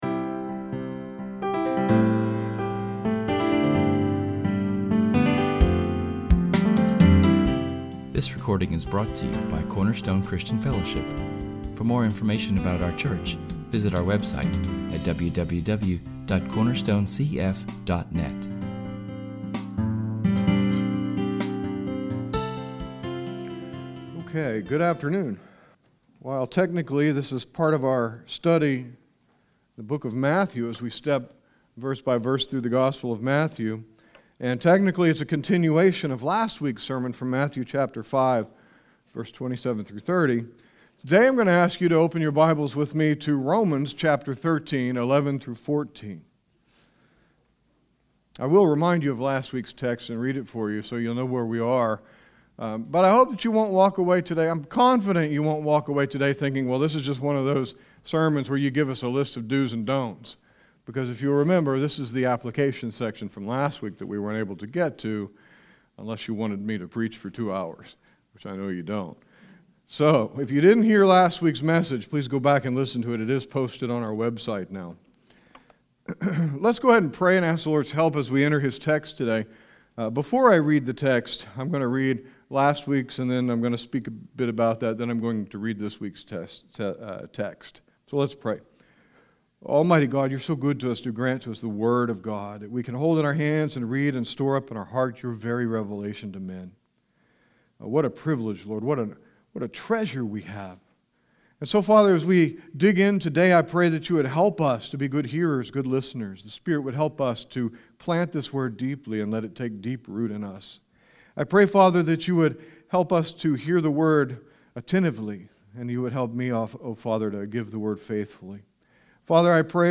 This sermon forms the application of our previous message from [esvignore]Matthew 5:27-30[/esvignore], but we will also visit [esvignore]Romans 13:11-14[/esvignore]. We will address some strategies for defeating lust and will draw from many texts throughout the Bible.